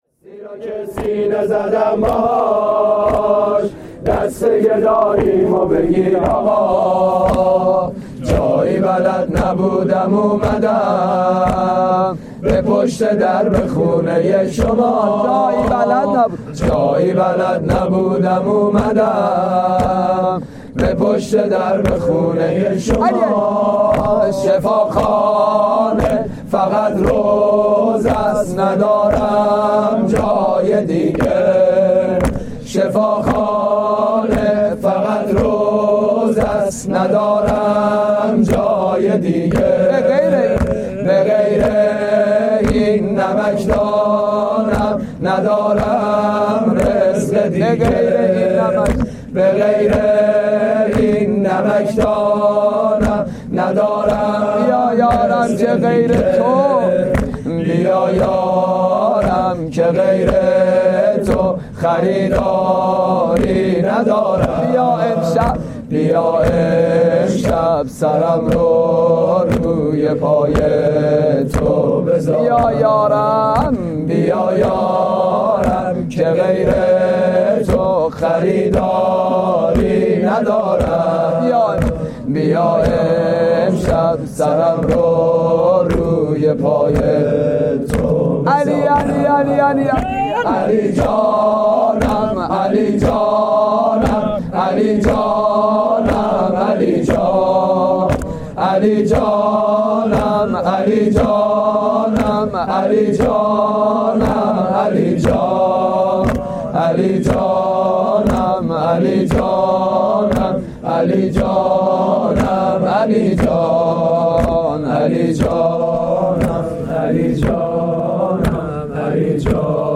جلسه هفتگی در مسجد کوفه